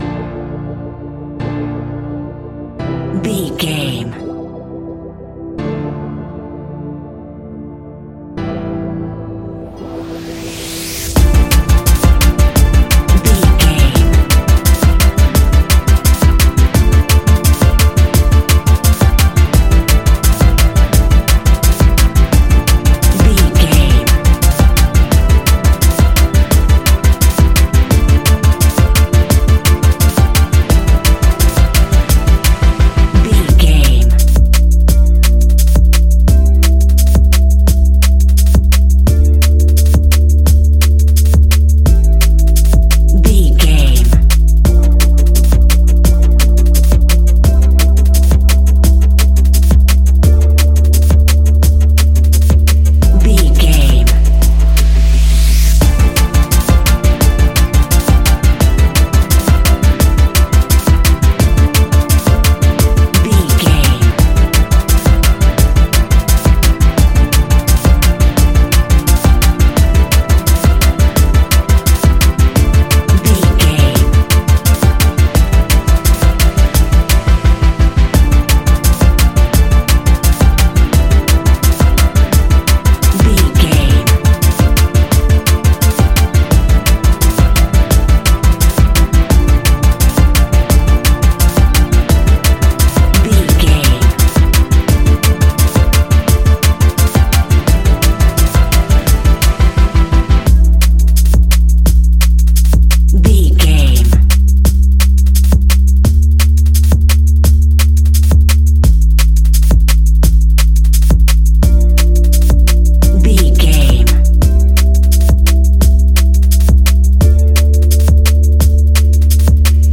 Ionian/Major
A♯
electronic
techno
trance
synths
synthwave
instrumentals